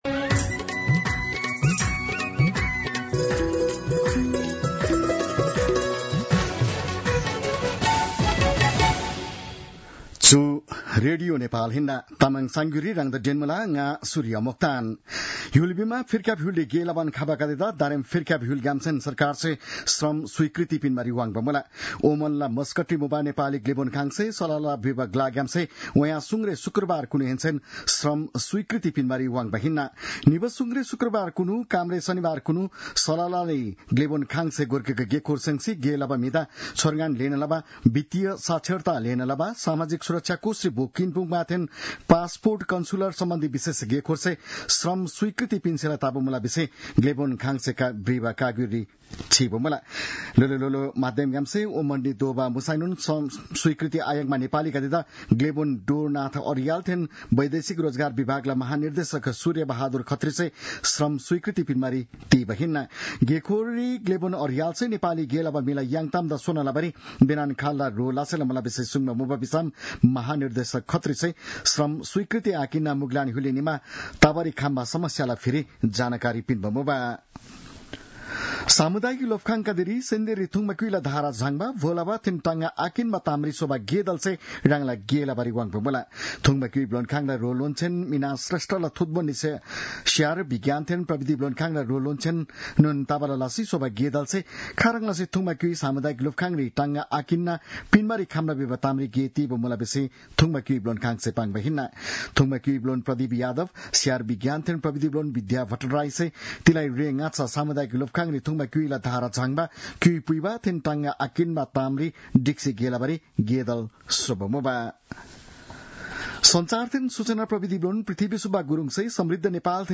तामाङ भाषाको समाचार : २४ माघ , २०८१